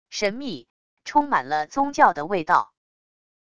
神秘……充满了宗教的味道wav音频